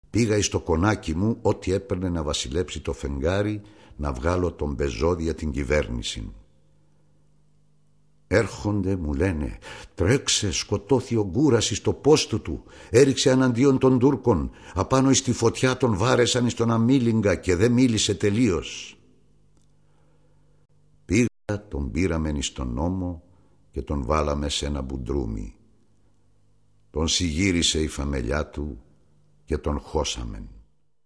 Θάνατος του Γκούρα (Ανάγνωσμα Από τα "Aπομνημονεύματα" του Μακρυγιάννη)